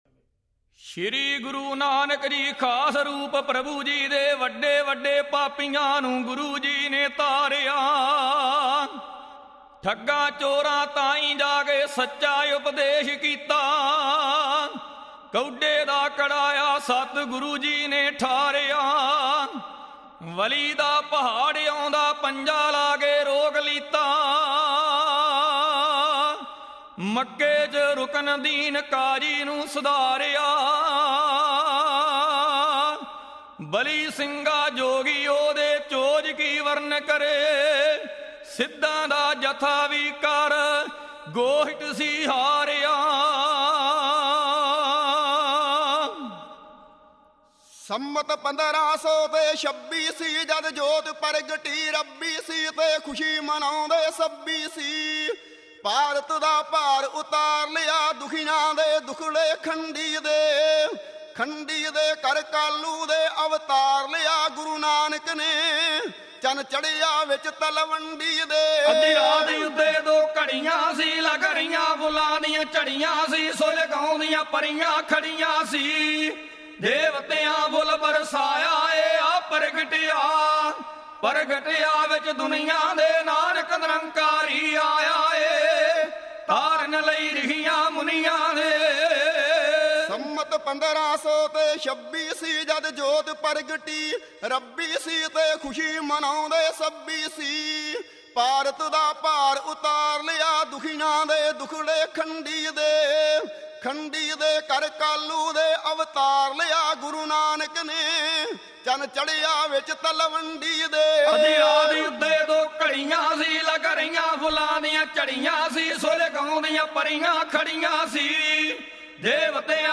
Genre: Kavishri